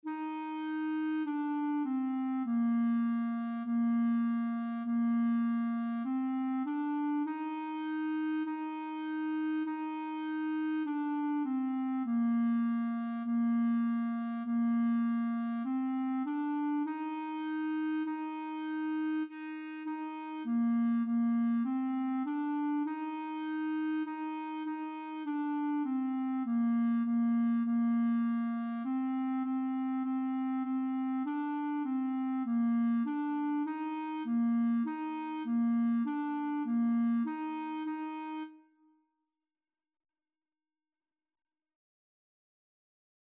4/4 (View more 4/4 Music)
Bb4-Eb5
Beginners Level: Recommended for Beginners
Clarinet  (View more Beginners Clarinet Music)
Classical (View more Classical Clarinet Music)